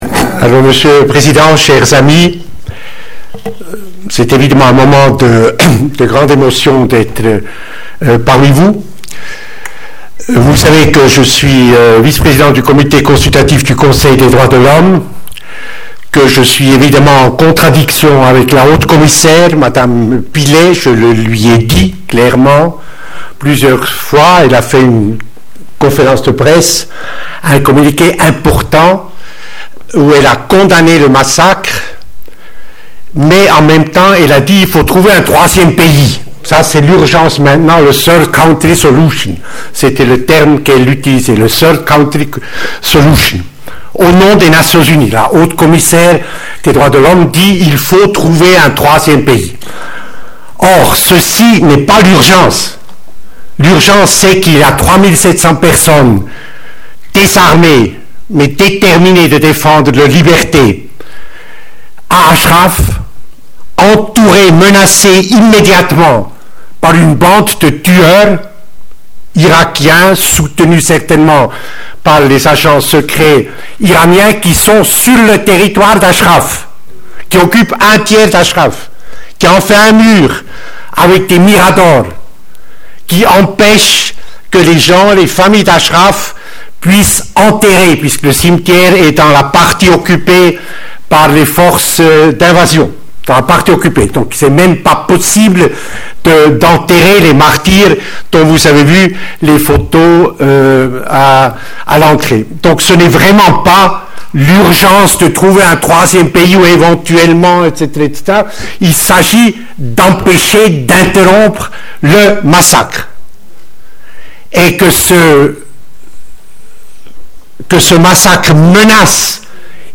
Ashraf - Intervention du samedi 16 avril 2011,
à Genève, par Monsieur Jean Ziegler